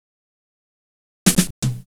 Fill 128 BPM (20).wav